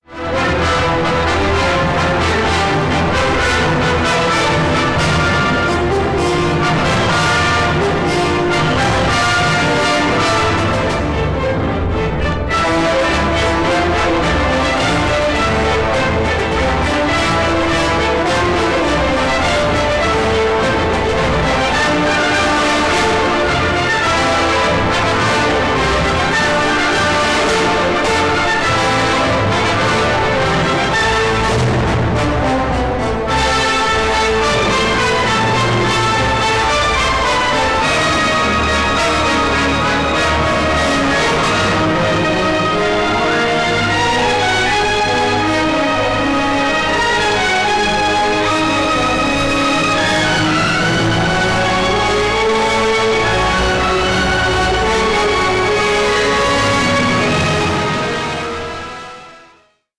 possente colonna musicale
Original track music